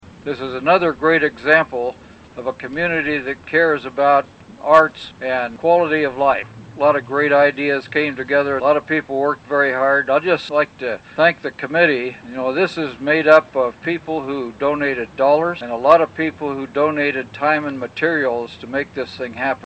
The project groundbreaking took place yesterday at the statue in City Park.
Mayor Mike Dodson comments.